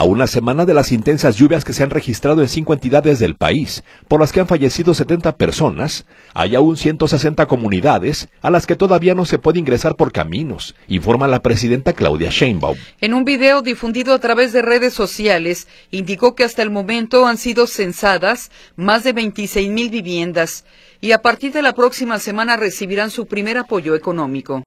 A una semana de las intensas lluvias que se registraron en cinco entidades del país, por las que han fallecido 70 personas, hay todavía 160 comunidades a las que todavía no se puede ingresar por caminos informa la presidenta Claudia Sheinbaum. En un video difundido a través de redes sociales, indicó que hasta el momento han sido censadas más de 26 mil viviendas y a partir de la próxima semana recibirán su primer apoyo económico.